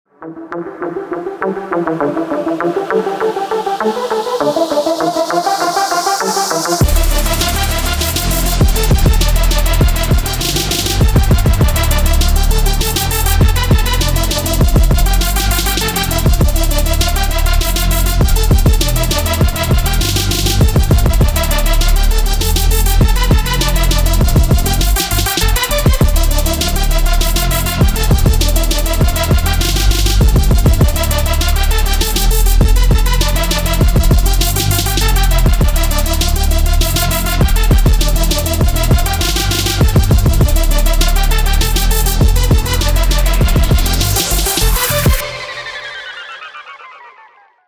• Качество: 192, Stereo
Electronic
без слов
Trap
Bass
Стиль: Witch house